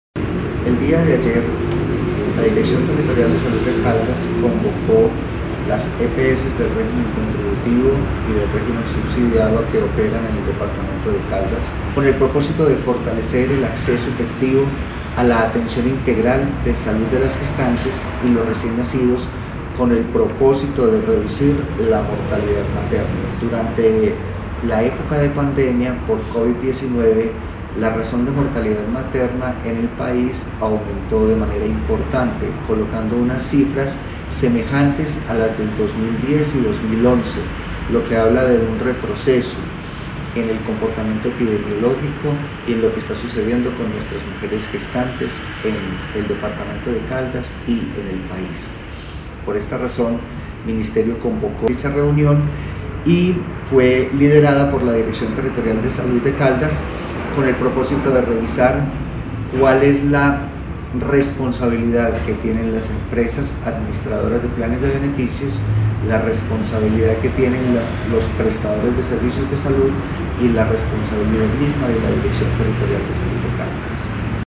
AUDIO-JORGE-RUBIO-JIMENEZ-DIRECTOR-TERRITORIAL-DE-SALUD-DE-CALDAS-TEMA-MATERNAS.mp3